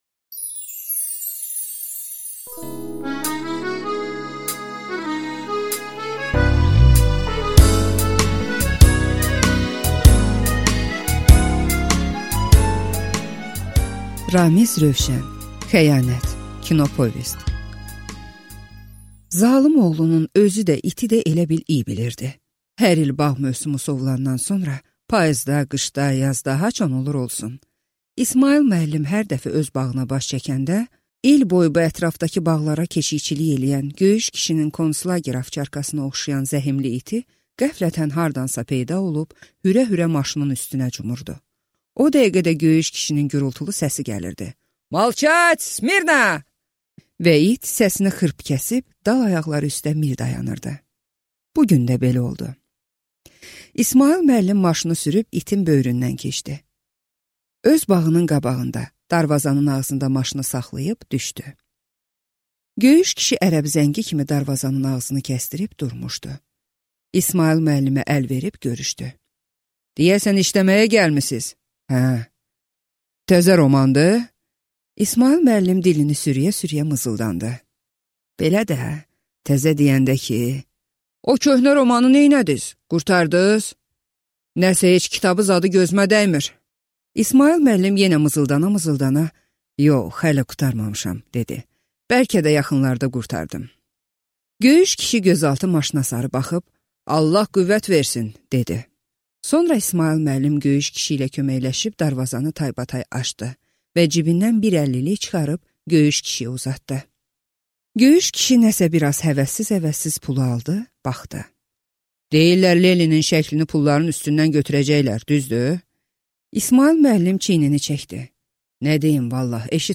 Аудиокнига Xəyanət | Библиотека аудиокниг